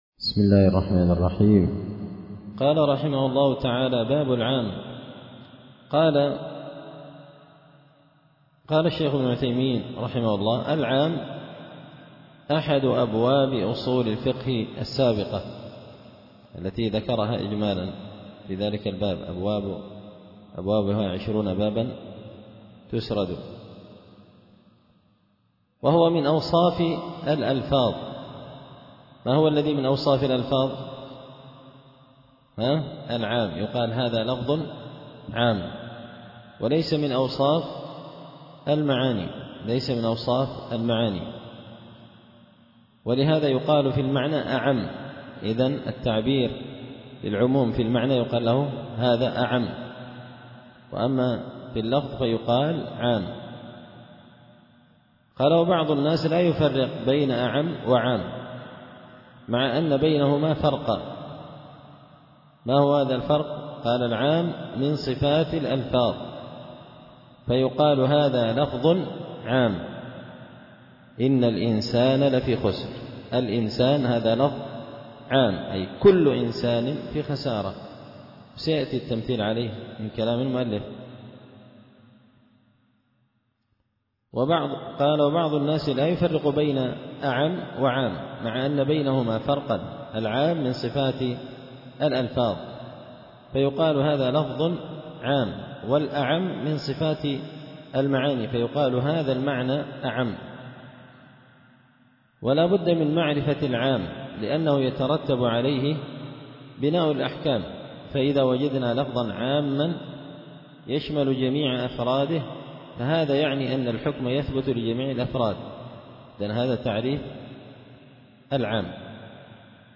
التعليقات على نظم الورقات ـ الدرس 22
دار الحديث بمسجد الفرقان ـ قشن ـ المهرة ـ اليمن